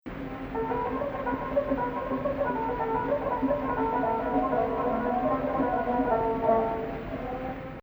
This very early recording is from the first decade of the 20th century, and it beautifully illustrates the Secondary Saba modulation, much more common at that time than later in the 20th century.
Maqam Rast
2nd Saba 6/8
Rast Fein Ya Gameel 15 2nd Saba.mp3